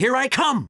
File:Fox voice sample SSBB.oga
Fox_voice_sample_SSBB.oga.mp3